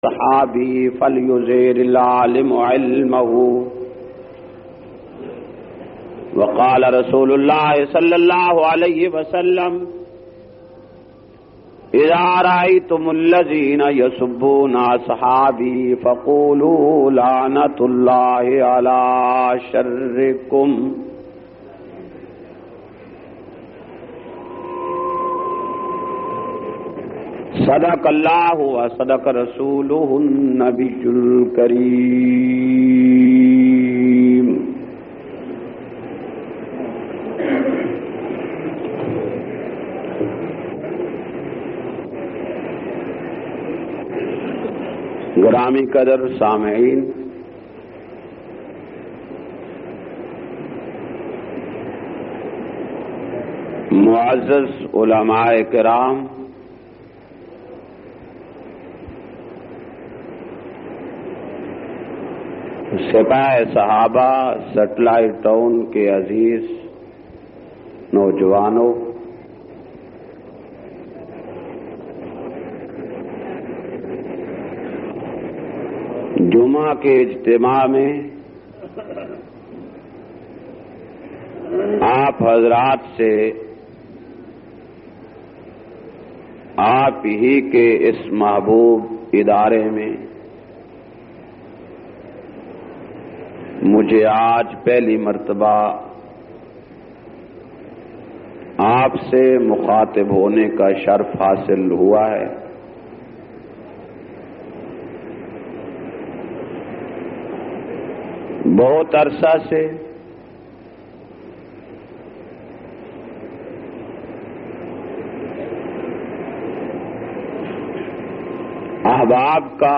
349- Shan e Usman o Bait e Rizwan Jamia Usmania Jumma Khutba Setlite town Jhang.mp3